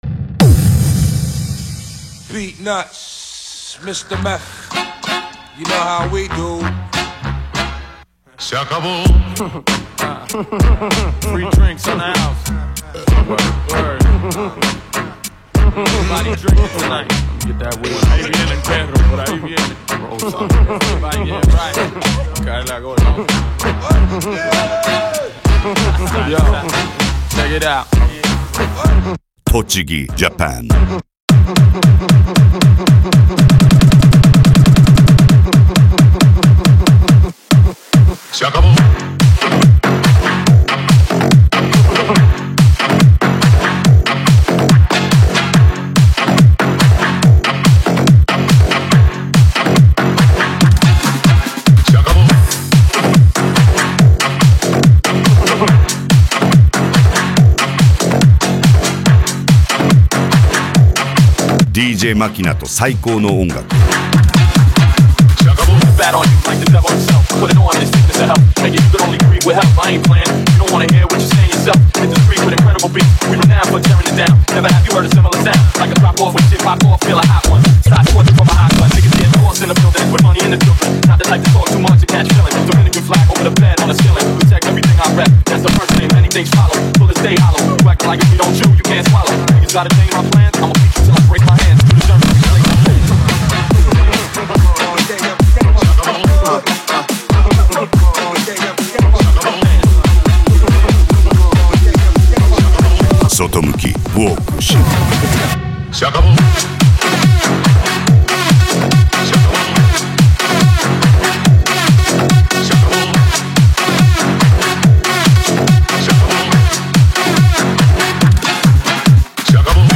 Hard Style
japan music
Minimal
PANCADÃO